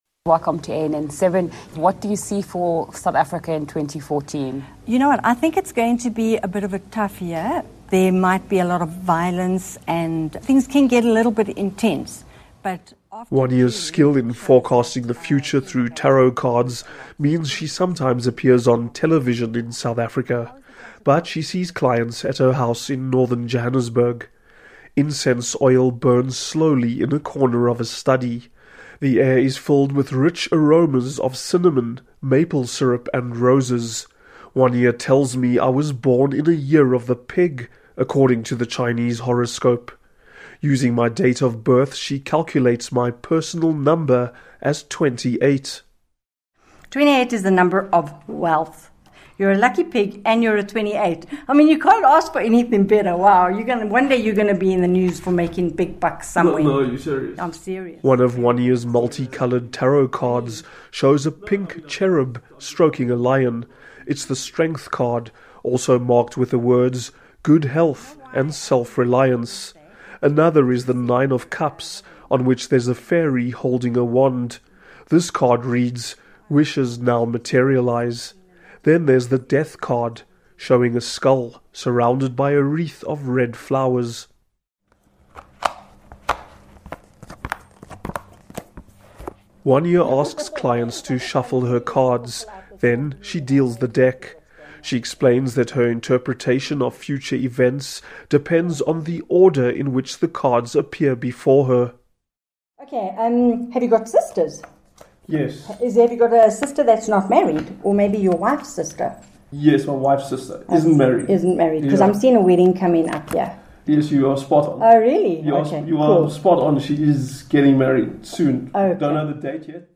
An interview with the tarot reader in Johannesburg